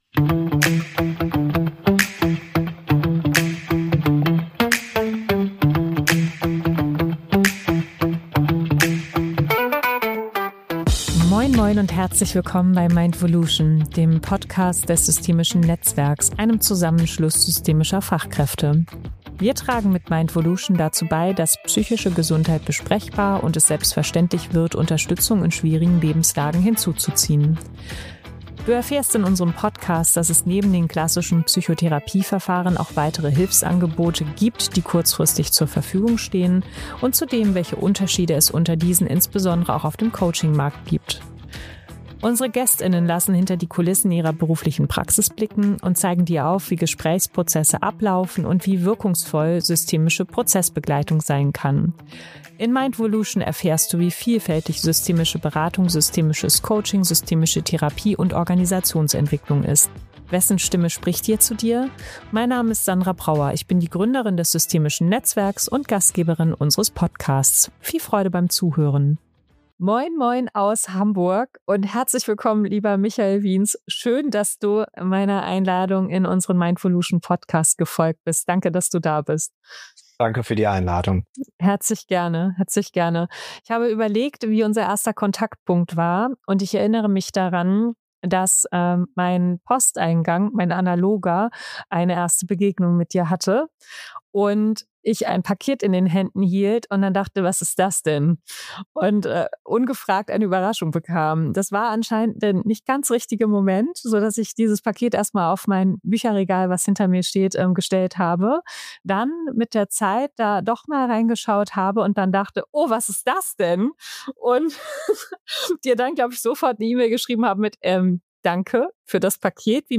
Ein Gespräch über Haltung statt Hype, Ethik in Beratung, den Umgang mit KI im Coaching und das große Bedürfnis nach echter Begegnung.